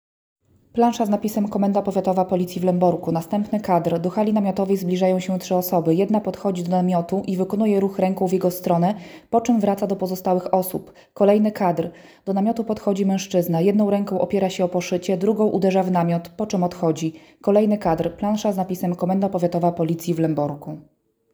Nagranie audio audiodeskrypcja_filmu.m4a